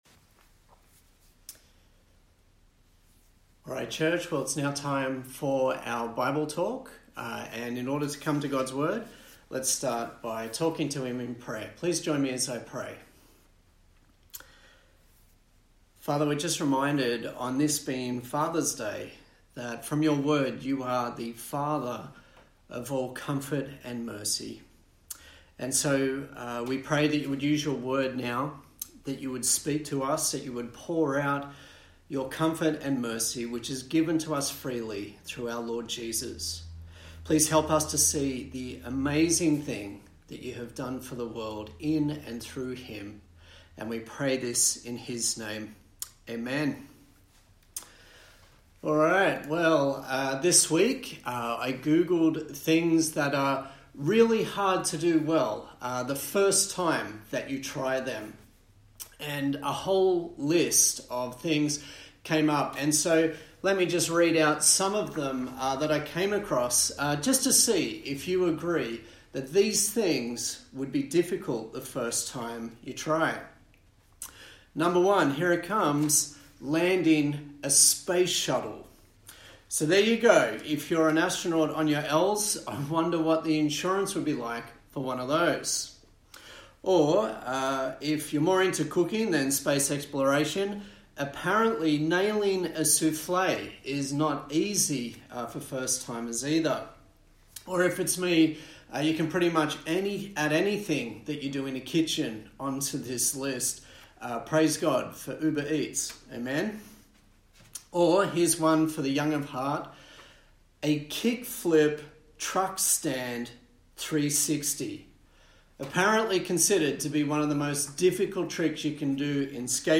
Service Type: Sunday Morning A sermon in the series on the book of Acts